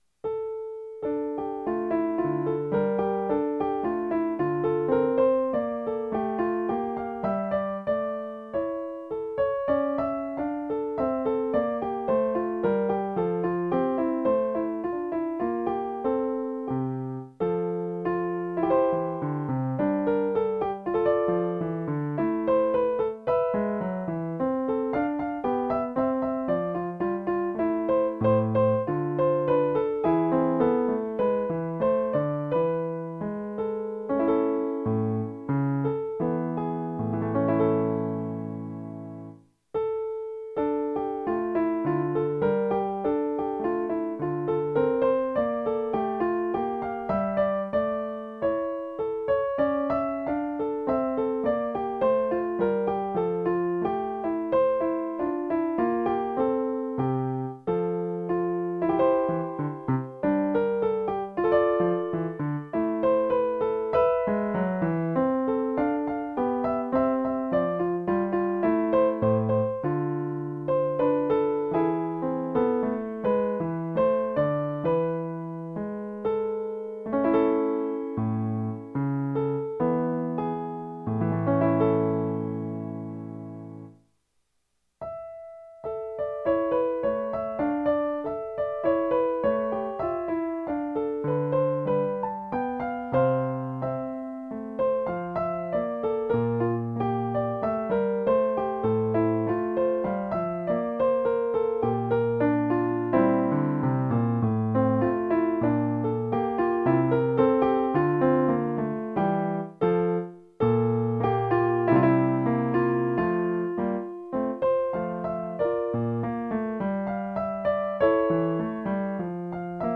Music / Classical
piano